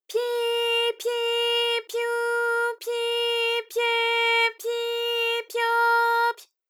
ALYS-DB-001-JPN - First Japanese UTAU vocal library of ALYS.
pyi_pyi_pyu_pyi_pye_pyi_pyo_py.wav